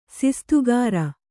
♪ sistugāra